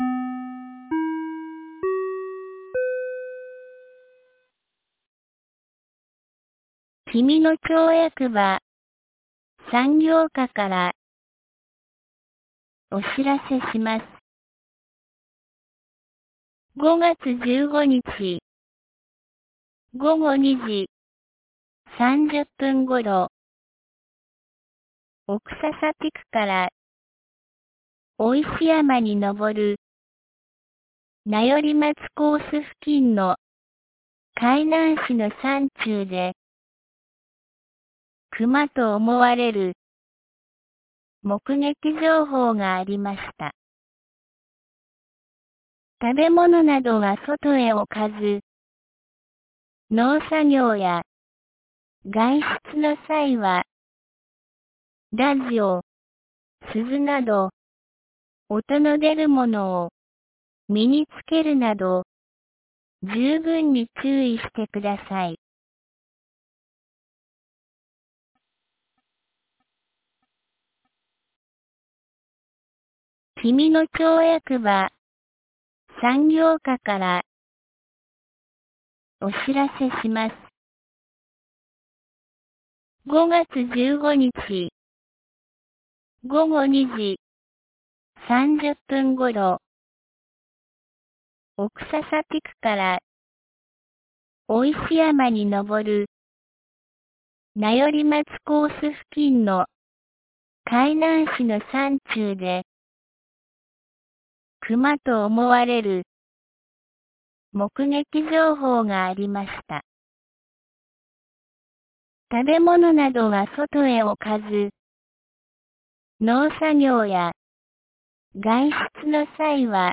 2021年05月16日 17時17分に、紀美野町より小川地区へ放送がありました。